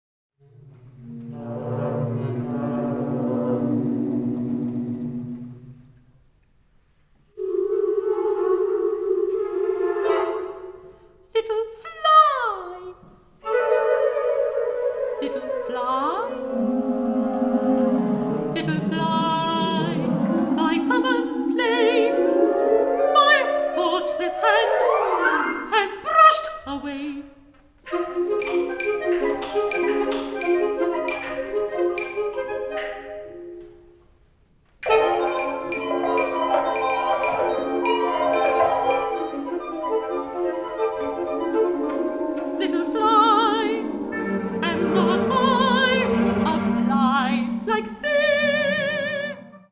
Category Concert/wind/brass band
Subcategory Original contemporary music (20th, 21st century)
Instrumentation Ha (concert/wind band)